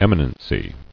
[em·i·nen·cy]